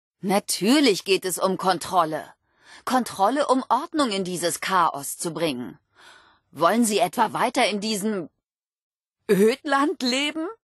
Vault-76-Aufseherin_00546bfb_1.ogg (OGG-Mediendatei, Dateigröße: 79 KB.